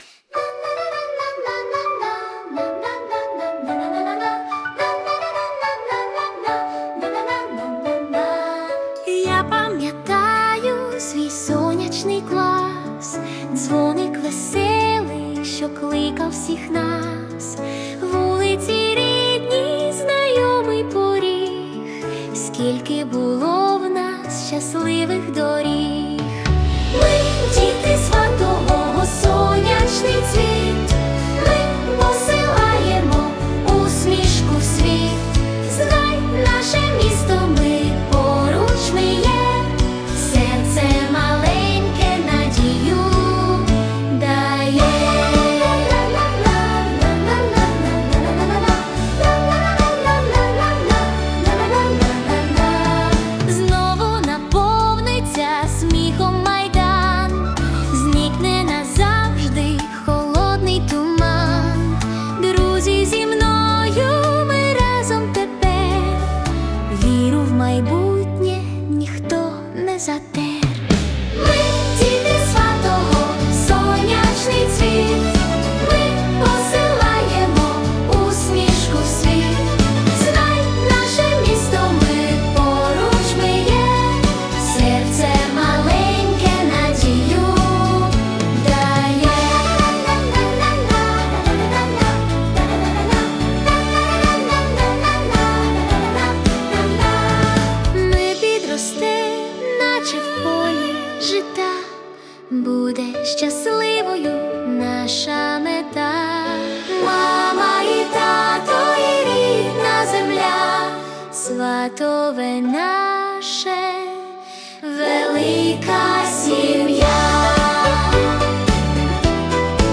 🎵 Жанр: Children's Pop / Orchestral